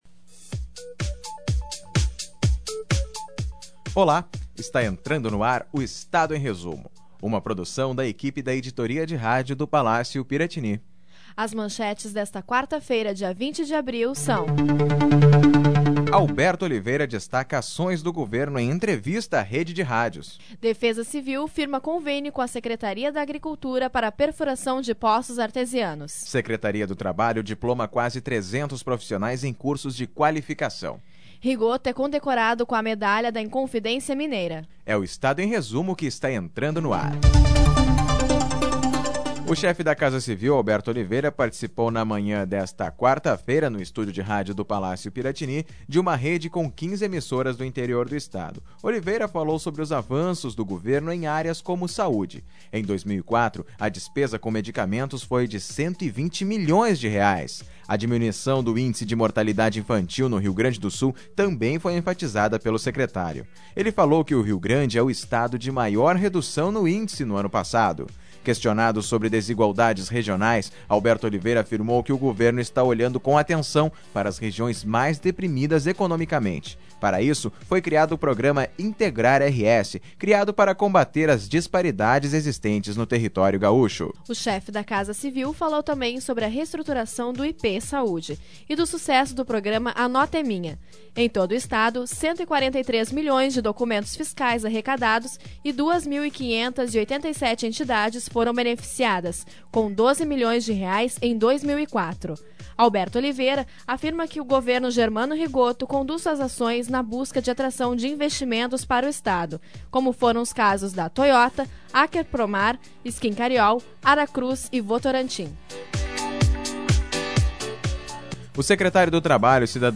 2005-04-20-resumo-de-noticias.mp3
Crédito: Estúdio de Rádio do Palácio Piratini